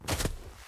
snowstep2.ogg